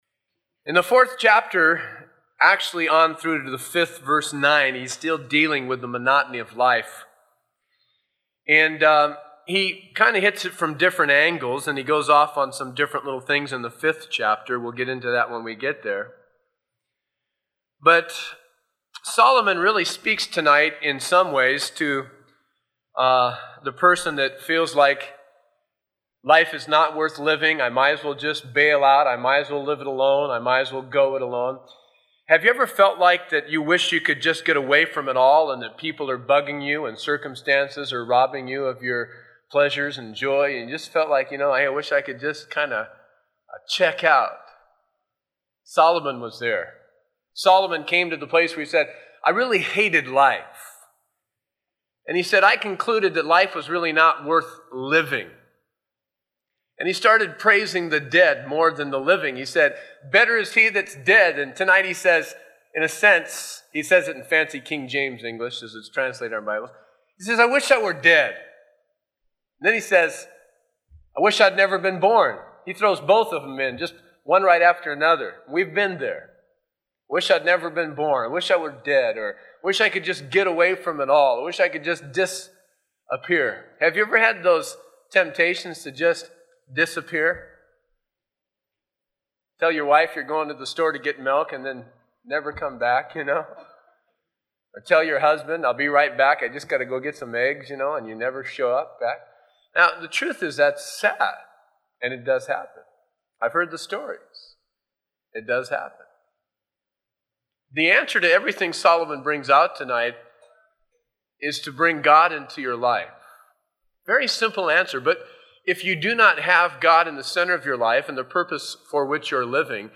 taught at Calvary Chapel San Bernardino